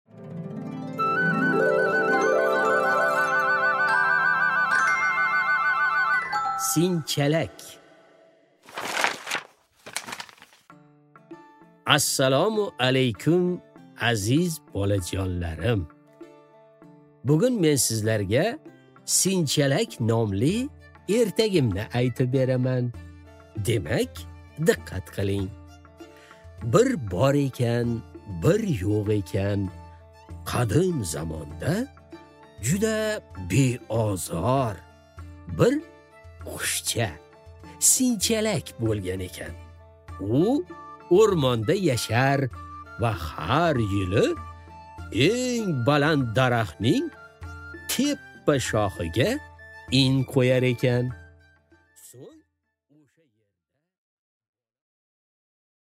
Аудиокнига Sinchalak